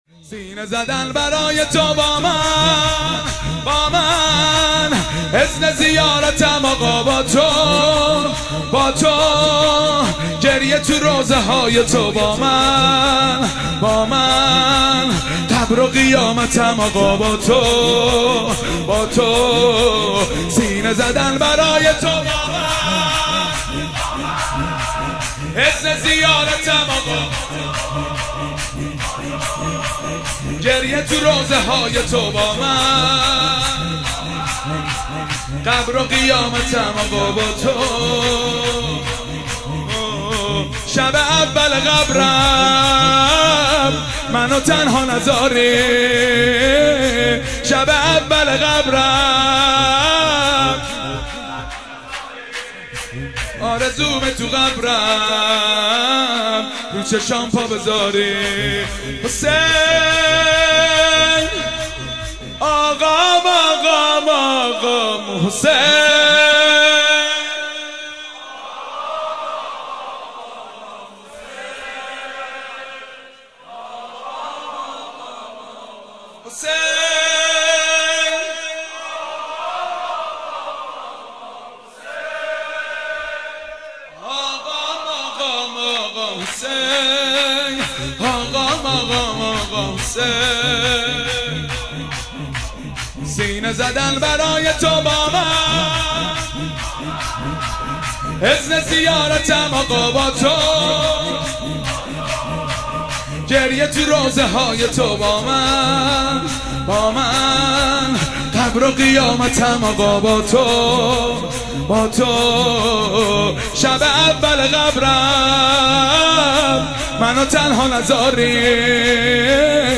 12-Shoor-2.mp3